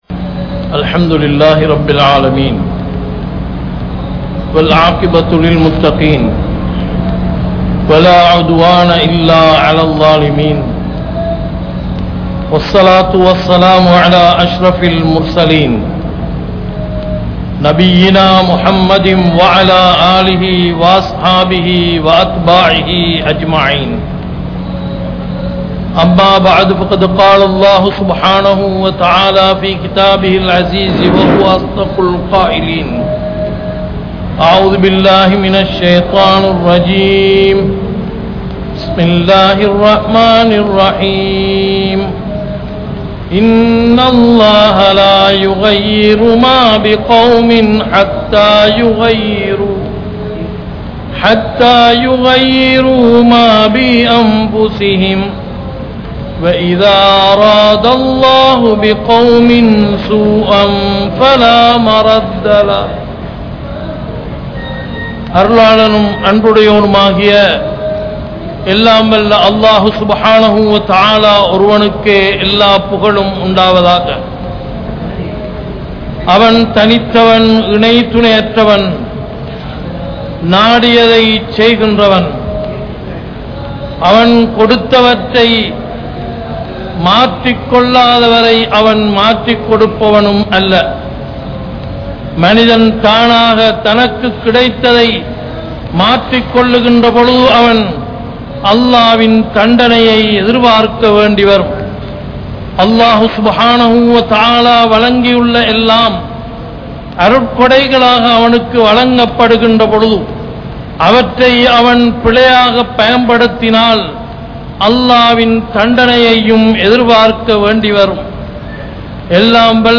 Unity(முஸ்லிம்களே ஒற்றுமைப்படுங்கள்) | Audio Bayans | All Ceylon Muslim Youth Community | Addalaichenai
Kollupitty Jumua Masjith